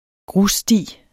Udtale [ ˈgʁus- ]